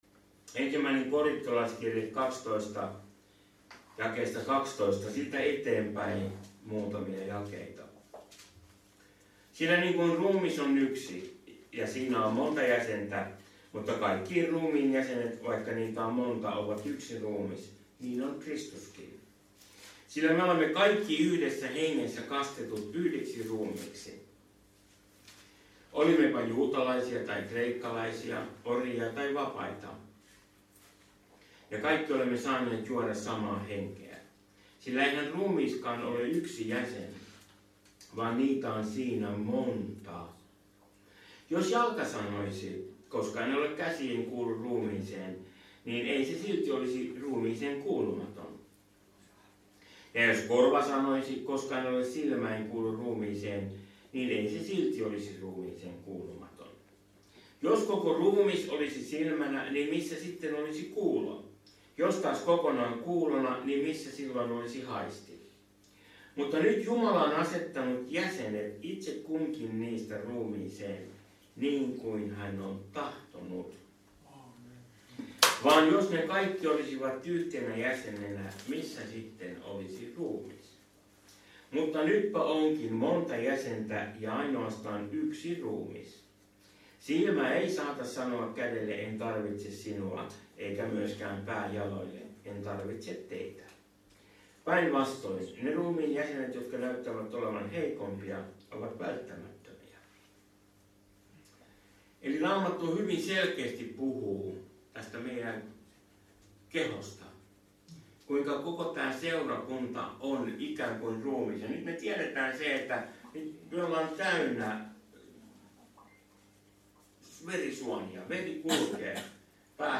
Vantaan Kotikirkko - Kuuntele puheita netissä
Service Type: Jumalanpalvelus